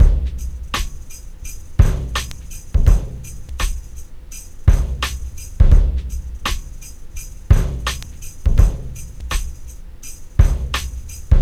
084bpm